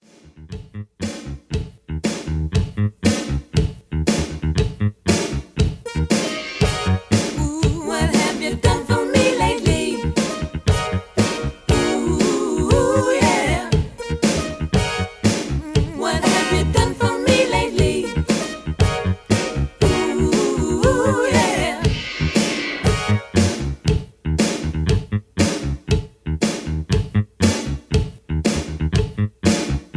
Key-Ebm) Karaoke MP3 Backing Tracks